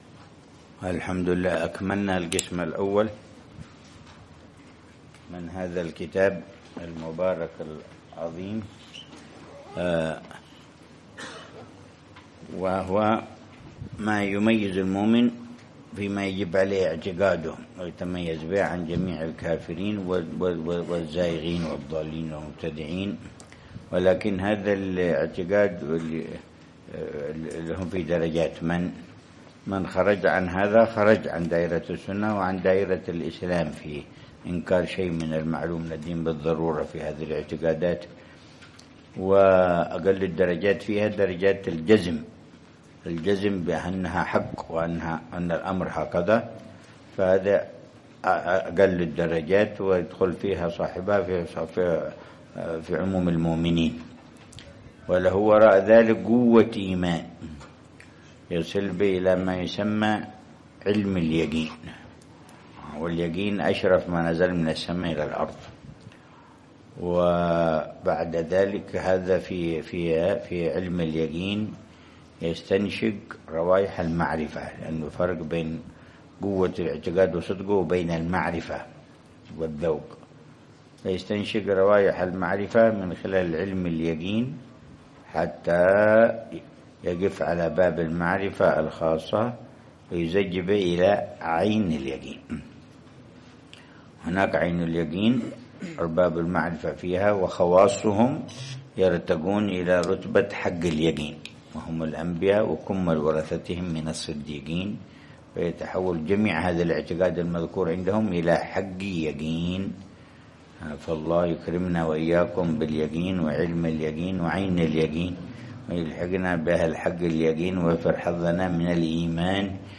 الدرس الخامس للعلامة الحبيب عمر بن محمد بن حفيظ في شرح كتاب: الأربعين في أصول الدين، للإمام الغزالي .